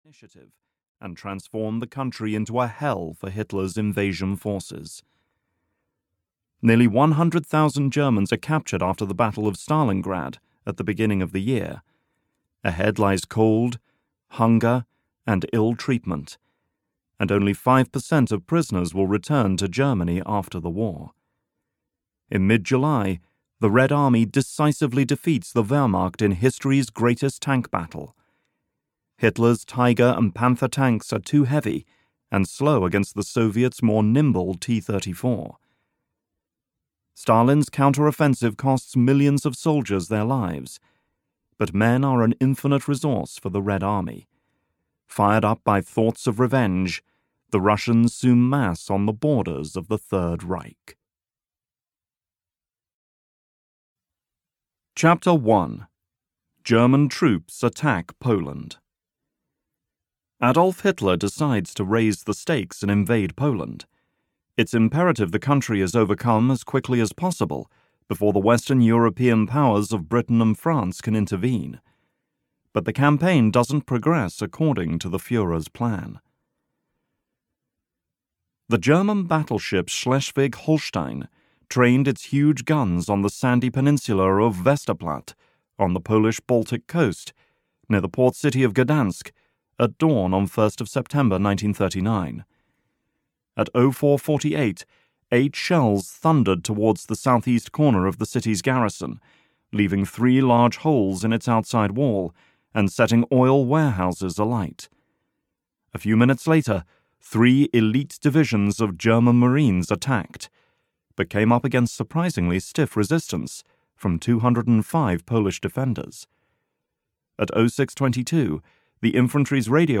Hell on the Eastern Front (EN) audiokniha
Ukázka z knihy